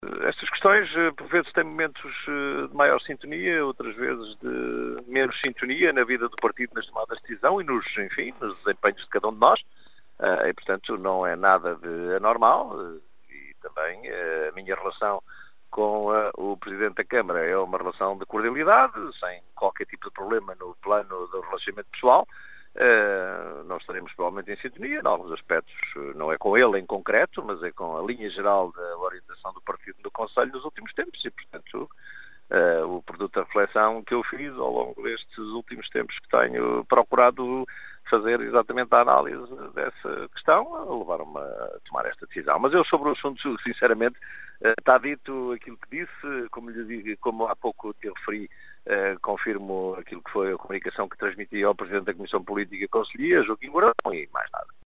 O socialista Jorge Fão em declarações à Rádio Caminha.